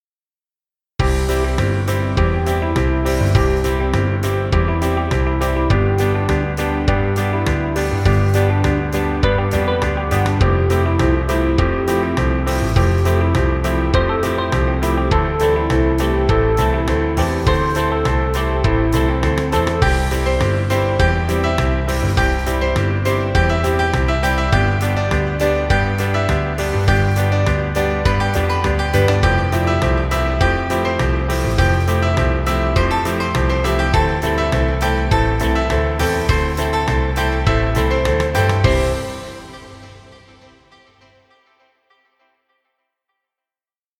light background track. Instrumental music for jazz band.
Jazz music for video.